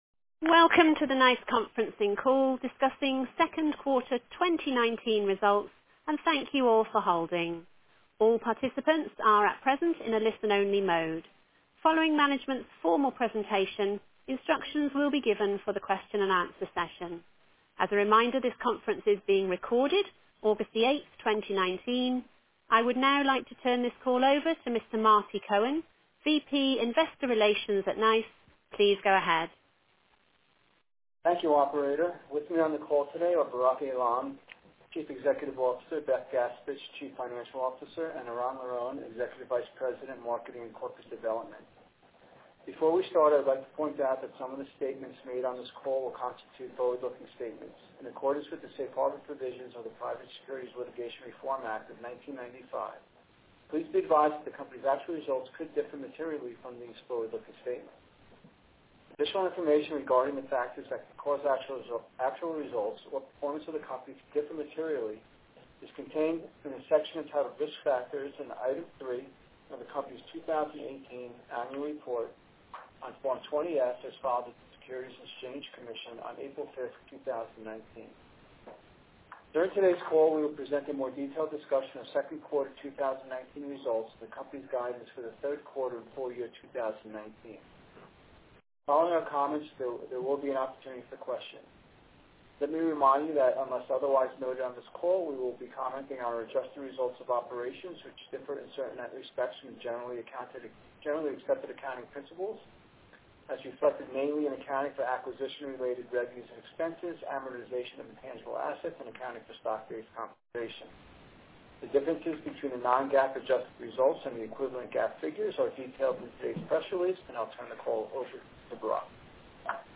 nice_q2_2019_conference_call.mp3